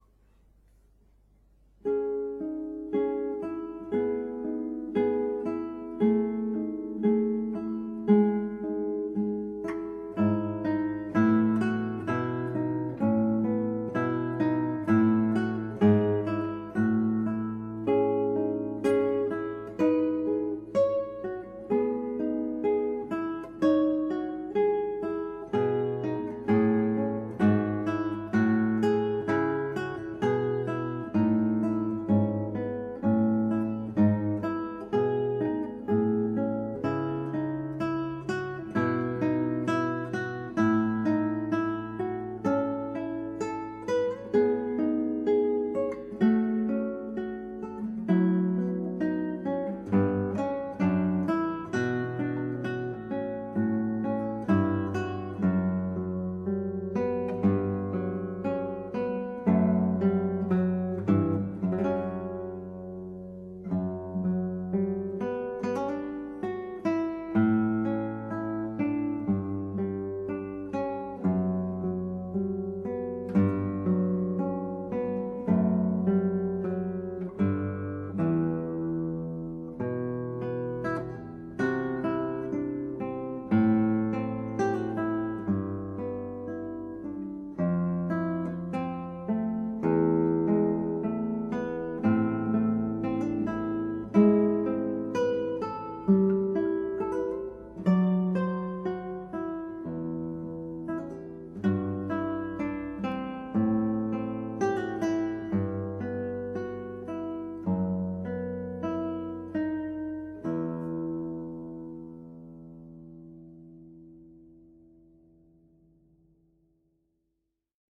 KOMPOSITIONEN FÜR  GITARRE  SOLO